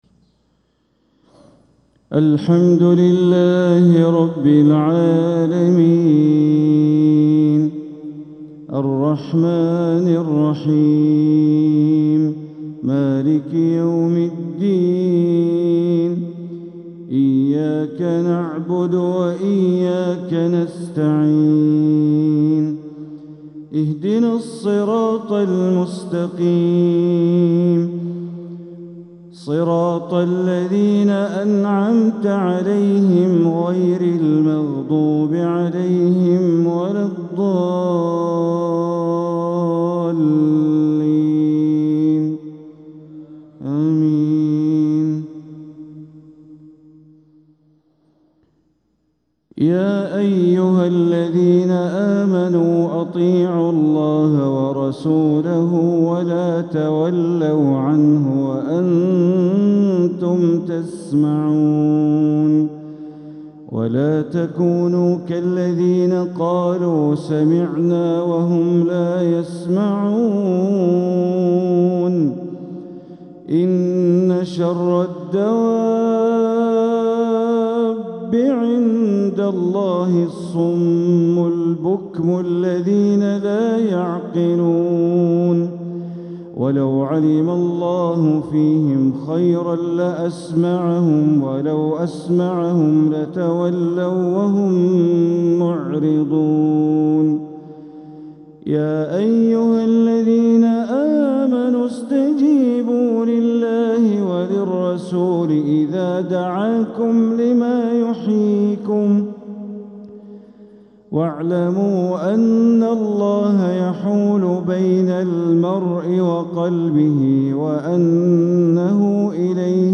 تلاوة من سورة الأنفال ٢٠-٣٥ | فجر الأربعاء ١٦ ربيع الآخر ١٤٤٧ > 1447هـ > الفروض - تلاوات بندر بليلة